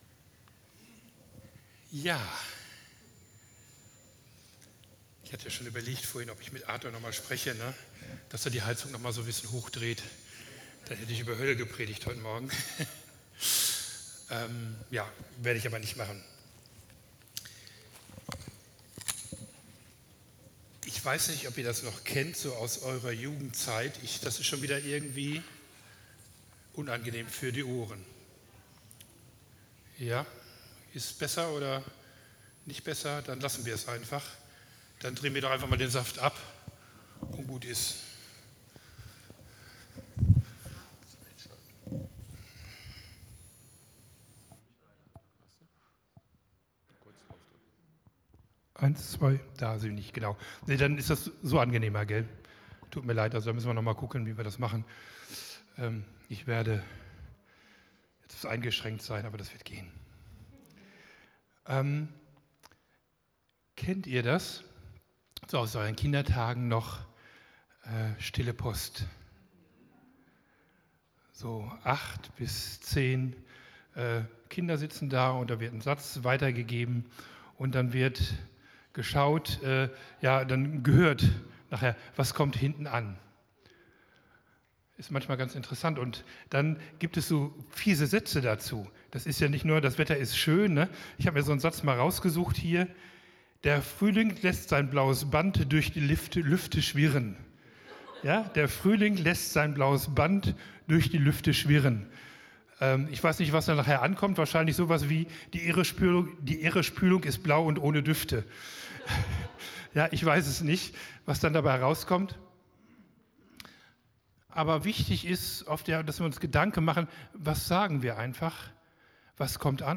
Genre: Predigt.